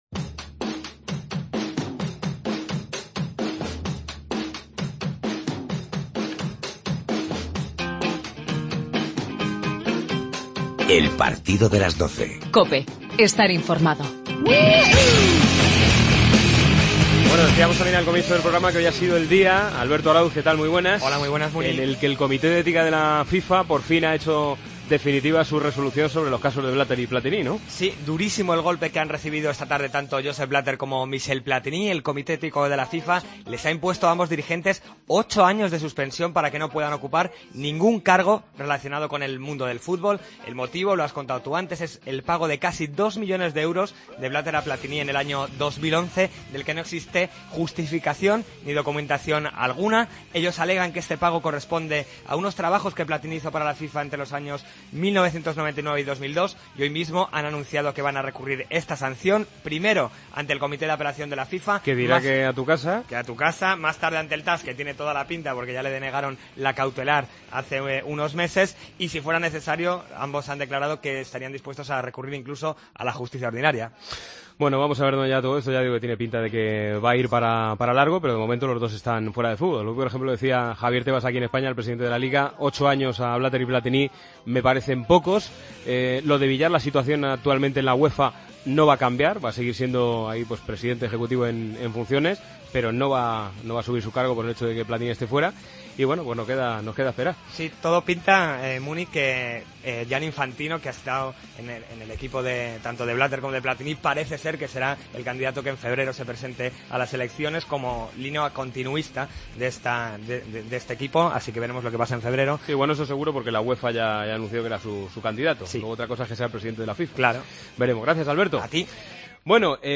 Hablamos con el técnico del Watford, Quique Sánchez Flores. Entrevista a Teresa Perales, la atleta paralímpica española más laureada.